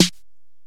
Snares
JJSnares (20).wav